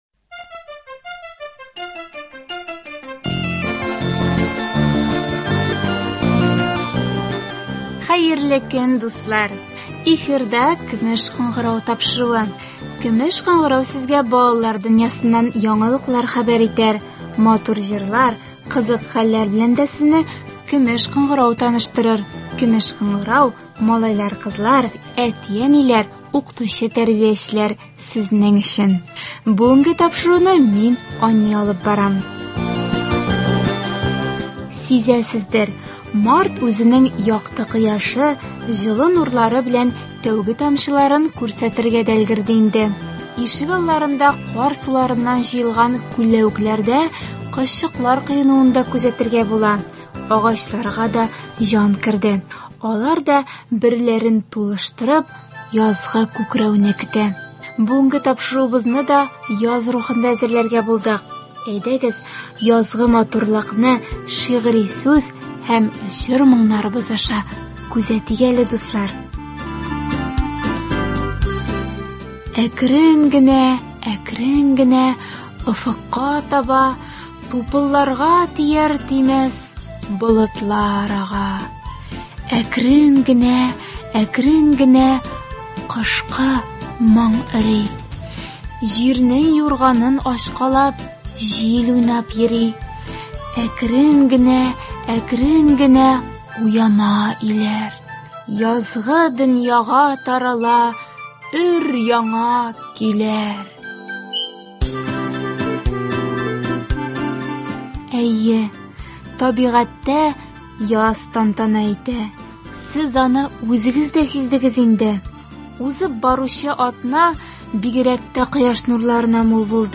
Бүгенге тапшыруыбызны яз рухында әзерләргә булдык. Әйдәгез, язгы матурлыкны шигъри сүз һәм җыр-моңнарыбыз аша күзәтик әле дуслар.